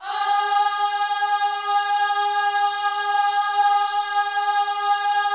OHS G#4B  -L.wav